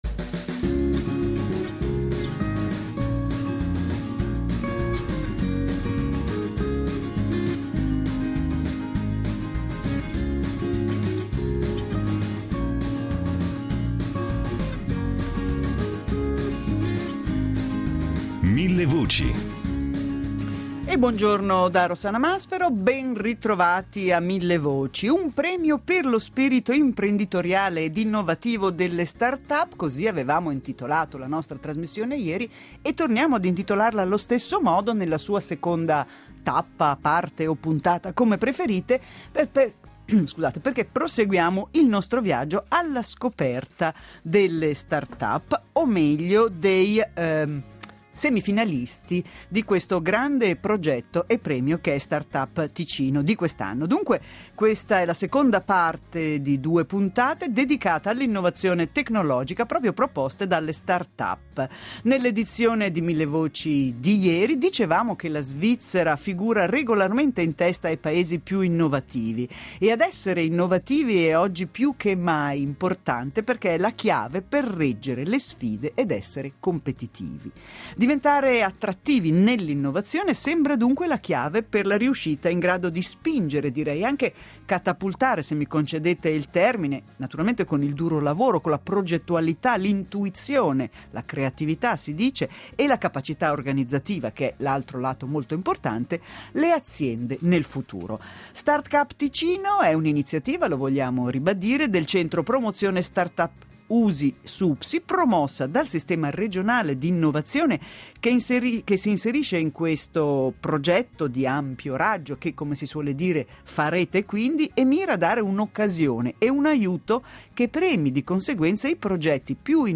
Millevoci torna a dare voce agli altri 5 finalisti ( i primi 5 erano ospiti nella puntata di ieri 27 novembre ), che potranno esporre i propri progetti in diretta.